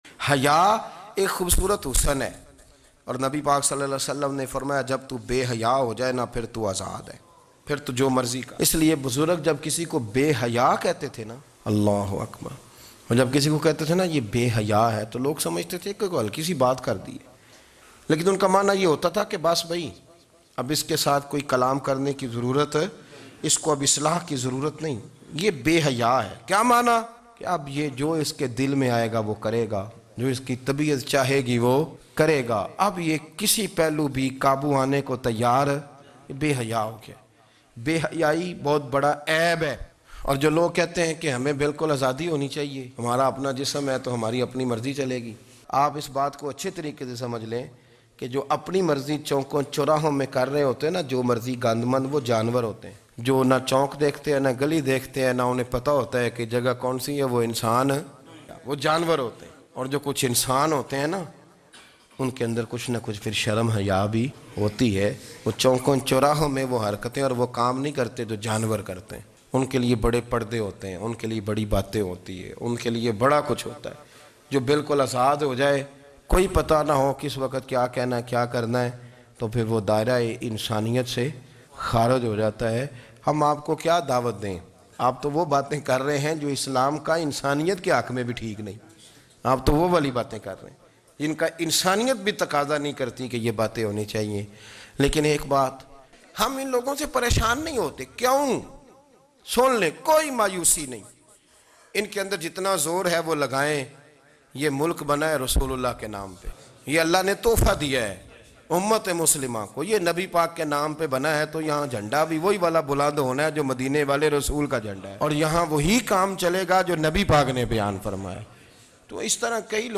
Jism Bhi Allah Ka Marzi Bhi Allah Ki Bayan